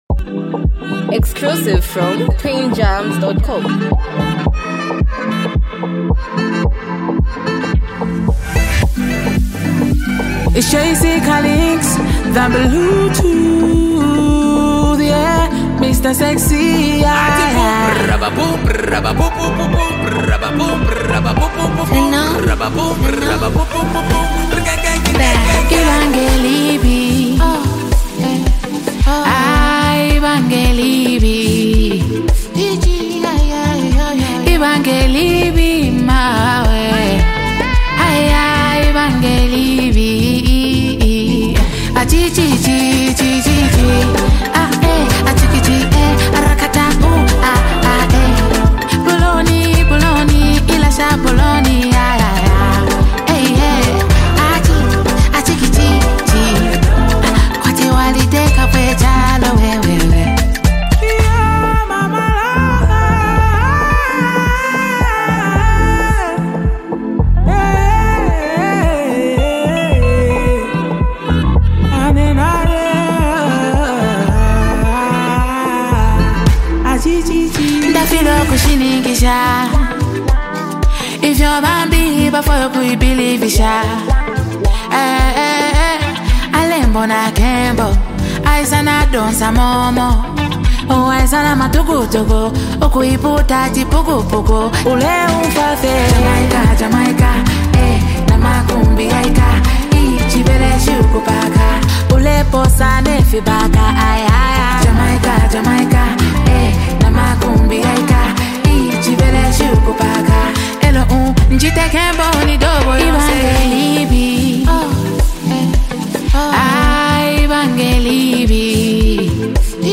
hype, street vibes, and a powerful hook
unique singing flow